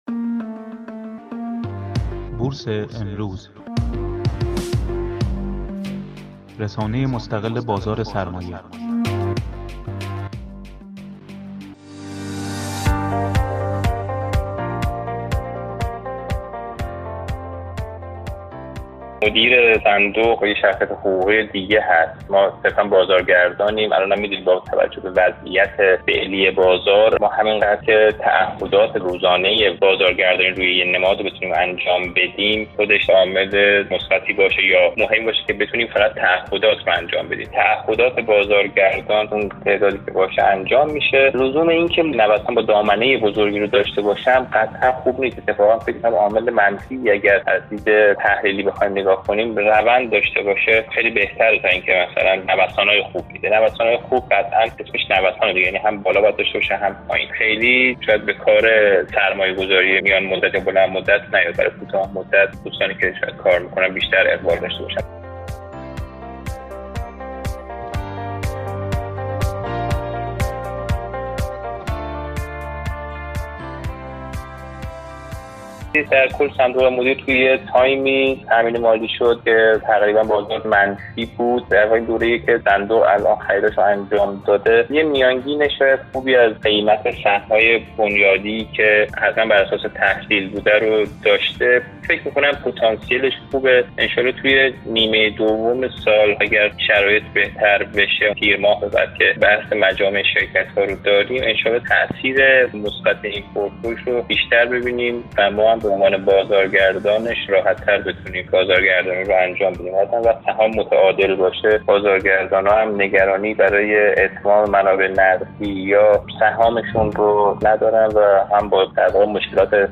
گفت و گو با خبرنگار بورس امروز